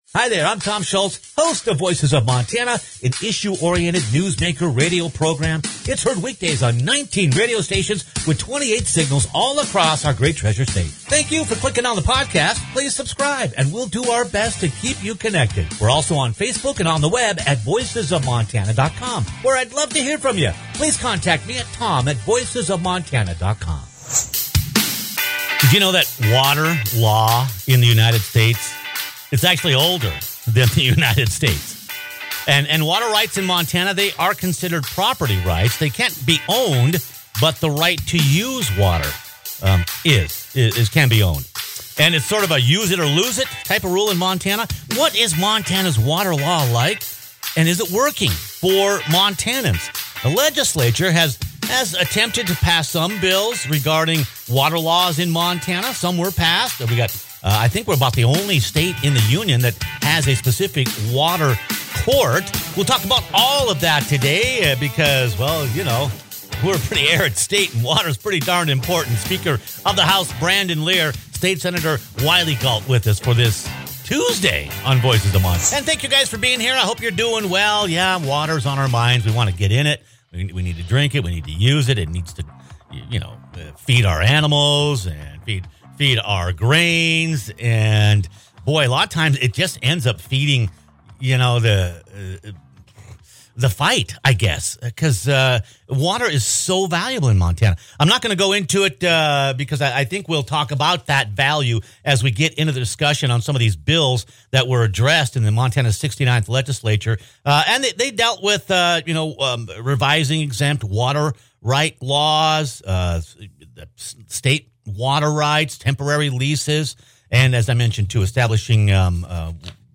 Montana Speaker of the House Brandon Ler and State Senator Wylie Galt discuss a handful of critical water issues addressed by the past, present, and likely future Montana legislature.